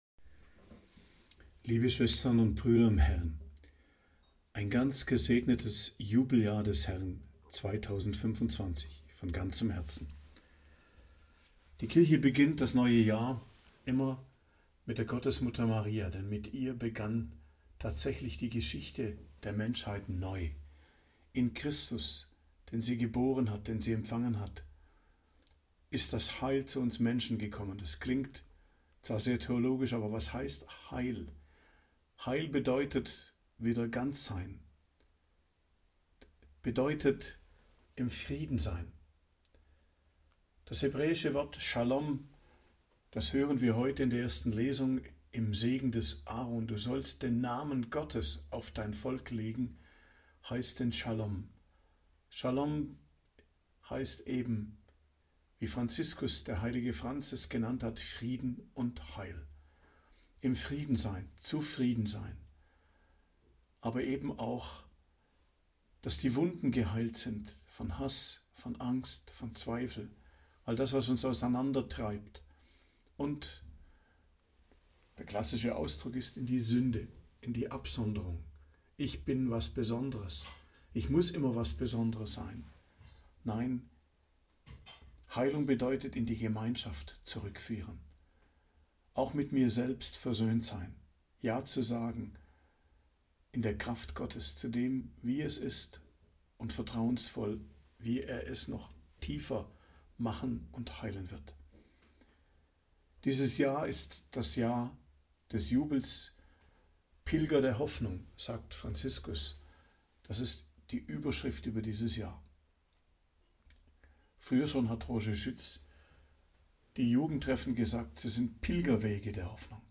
Predigt an Neujahr, Hochfest der Gottesmutter Maria, 1.01.2025